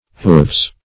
Hooves - definition of Hooves - synonyms, pronunciation, spelling from Free Dictionary
Hooves (h[=oo]vz).